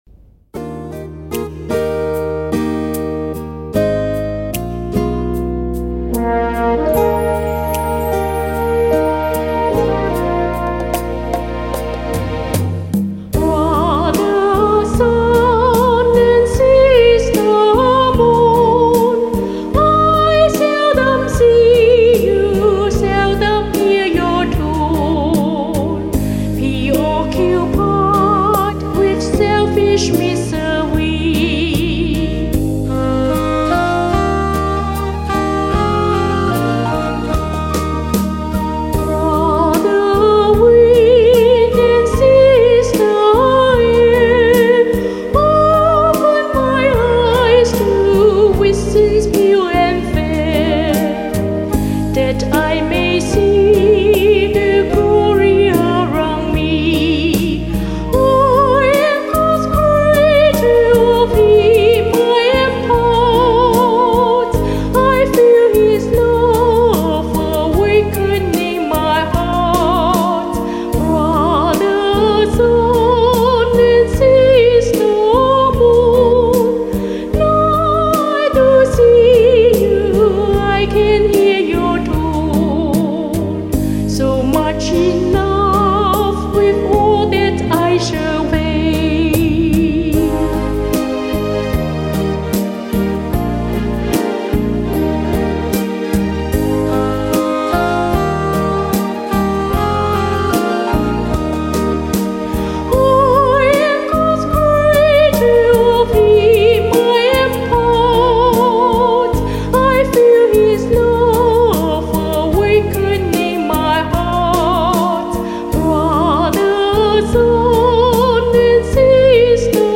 英文合唱 Choral in English